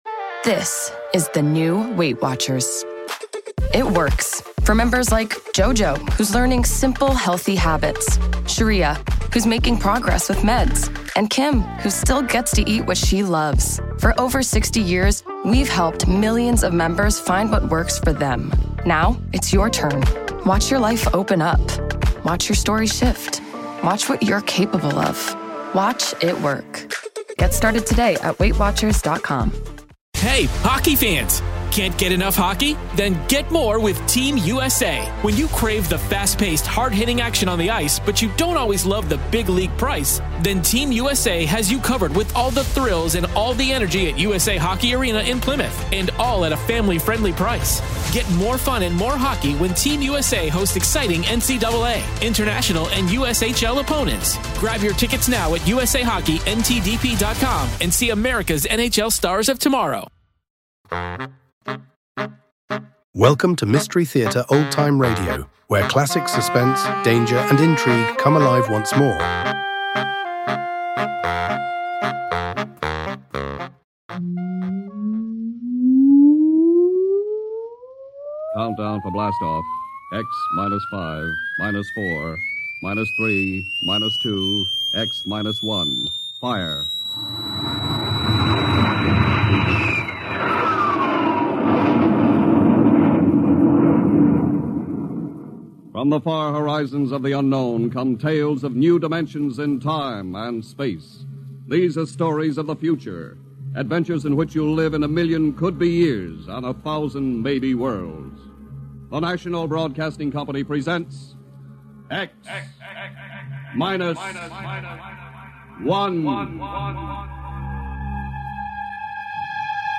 First broadcast on April 24, 1955 , this legendary Old Time Radio sci-fi drama launches listeners into a gripping mystery at the edge of the universe. In this classic episode, humanity’s attempts to explore deep space are brought to a halt by a strange, invisible barrier . Five space expeditions have vanished without a trace—no recordings, no signals, no survivors.
Whether you're a longtime fan of classic radio dramas or discovering X Minus One for the first time, this episode delivers timeless storytelling, atmospheric sound design, and the thrilling exploration themes that defined 1950s science fiction.